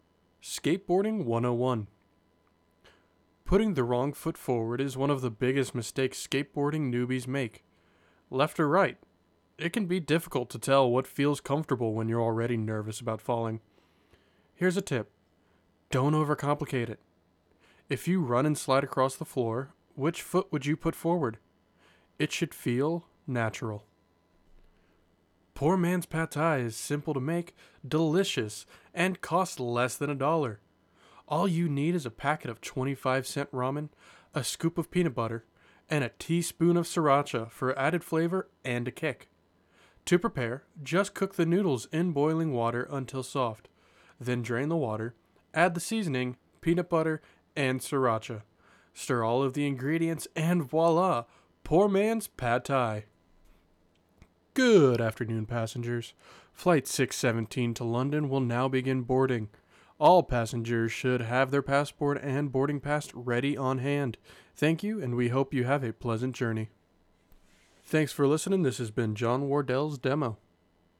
Business
English - USA and Canada
Young Adult
Business_Demo.mp3